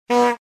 Play, download and share bocina humor original sound button!!!!
bocina-humor.mp3